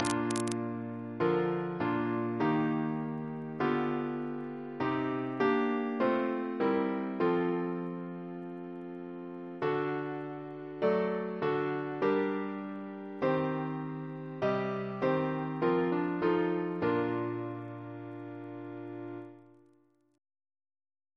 Double chant in B♭ Composer: George Thalben-Ball (1896-1987), Organist of the Temple Church Reference psalters: ACP: 138; RSCM: 134